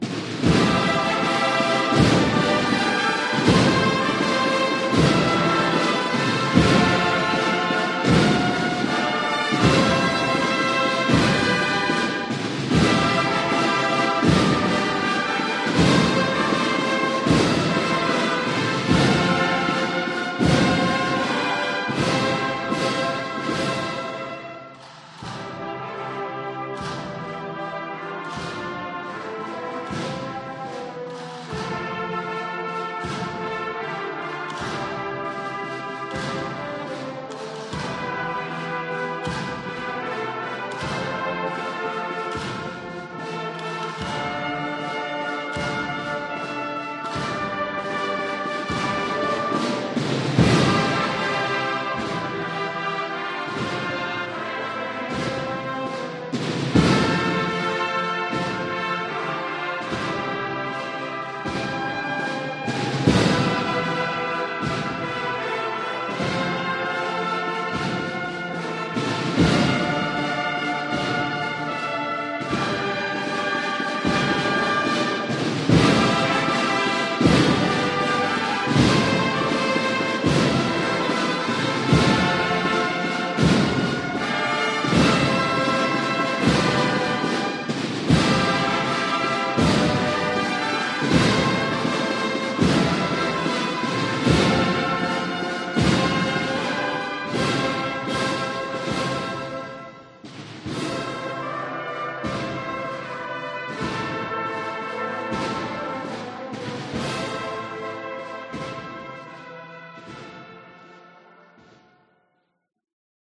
Nazareno y Gitano, por la Agrupación Musical Santísimo Cristo del Perdón (Valladolid)
La ya extinta Banda de Cornetas y Tambores Santísimo Cristo del Perdón fue de las primeras en Valladolid —concretamente, en el año 1996— en adaptar su repertorio, pasando de interpretar marcha de estilo militar a marchas procesionales.